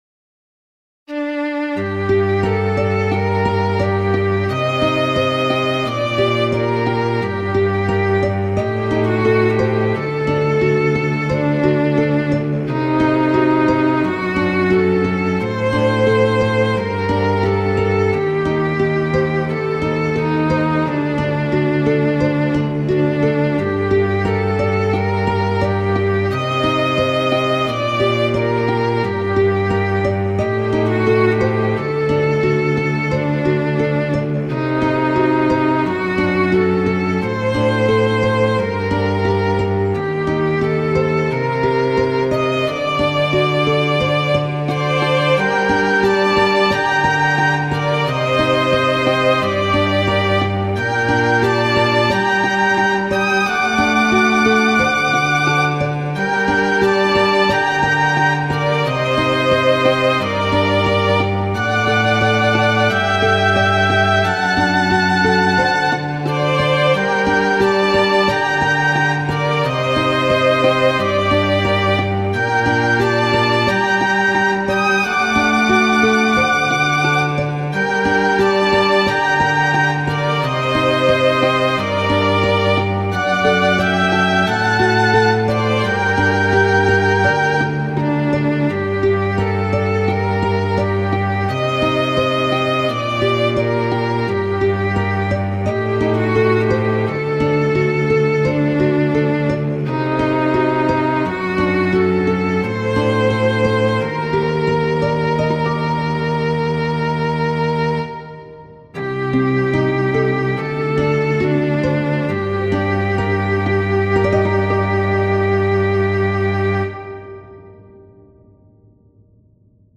クラシックショートスローテンポ暗い